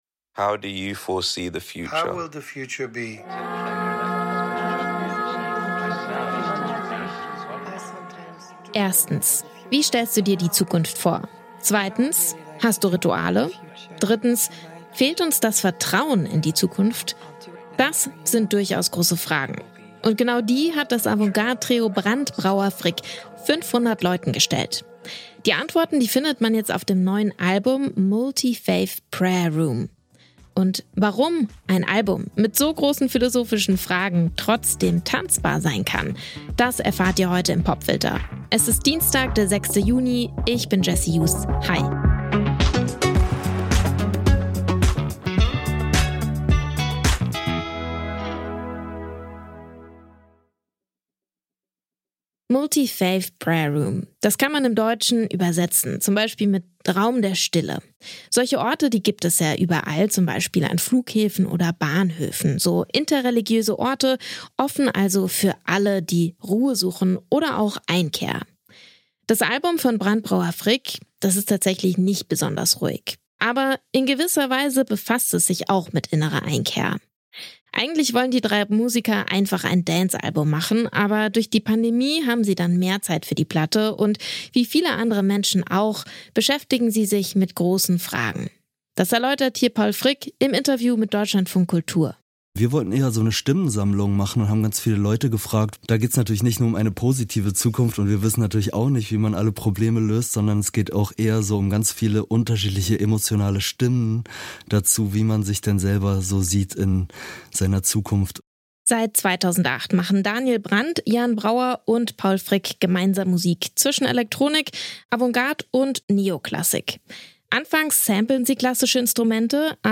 Mit Musik zwischen Elektronik, Avantgarde und Neo-Klassik ist das Berliner Trio Brandt Brauer Frick bekannt geworden. Auf dem neuen Album „Multi Faith Prayer Room“ dominieren die Clubsounds, obwohl es um große philosophische Fragen geht.